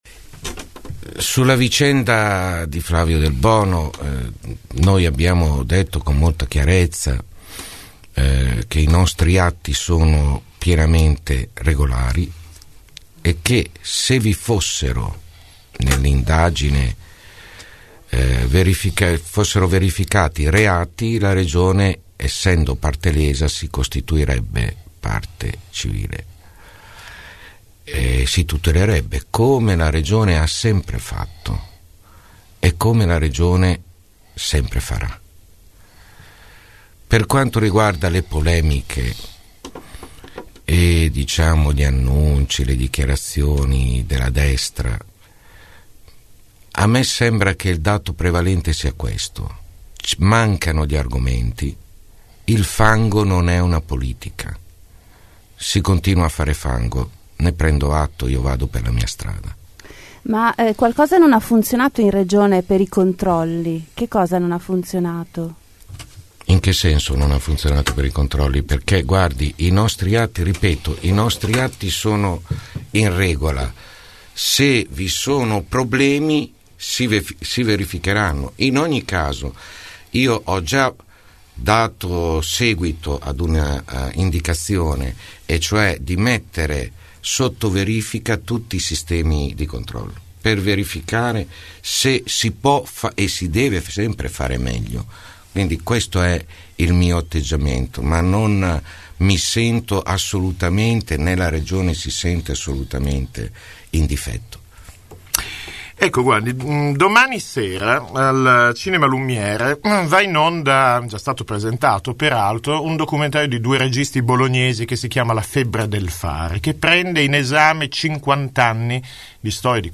16 mar. – Si concludono con il presidente uscente e candidato del centrosinistra, Vasco Errani, le interviste di Città del Capo – Radio Metropolitana ai candidati alle prossime elezioni regionali (28 – 29 marzo).